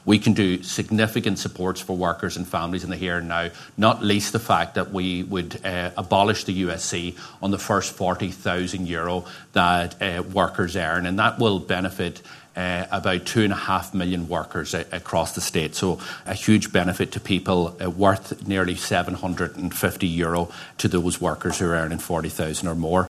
Sinn Fein TD Pearse Doherty says the party’s call for a ‘fair tax package’ would help struggling workers and families………..